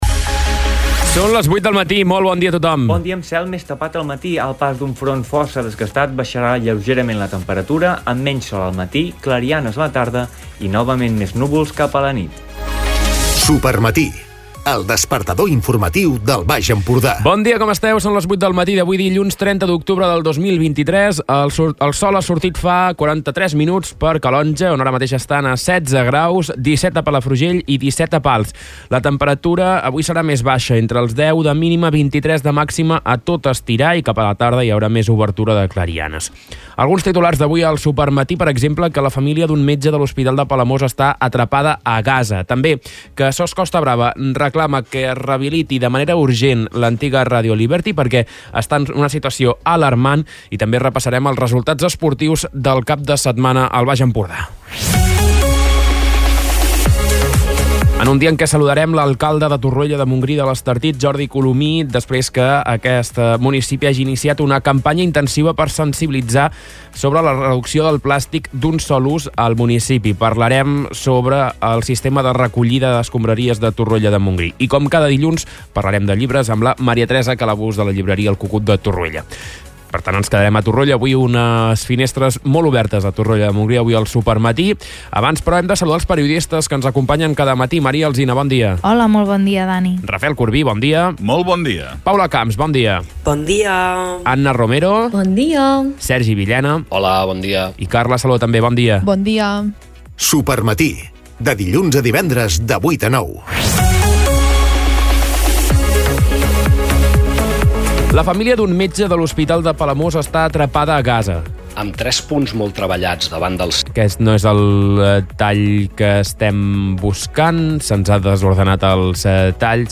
Escolta l'informatiu d'aquest dilluns